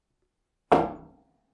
手榴弹投掷
描述：拉开手榴弹的销子，把它扔出去，然后它就爆炸了，爆炸的威力相当大，所以它很可能把什么大东西炸飞了
标签： 手榴弹 效果 投掷 声音
声道立体声